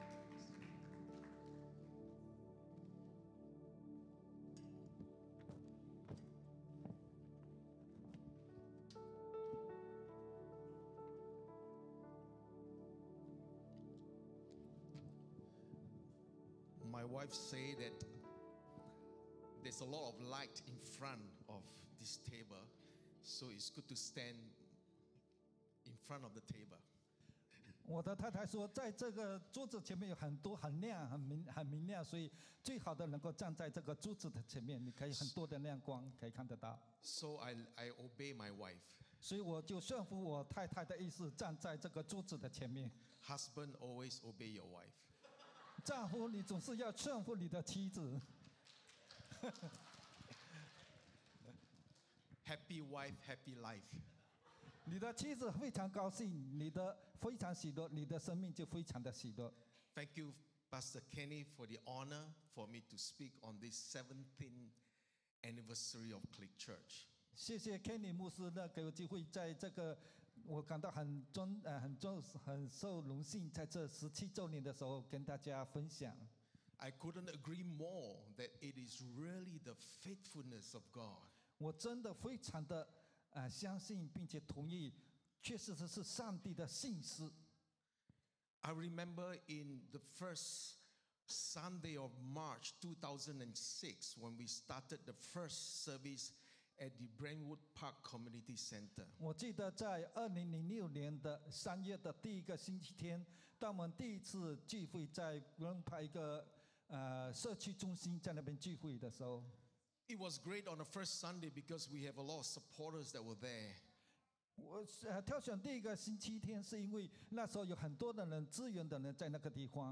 Bilingual Worship Service - 12th March 2023
Sermon Notes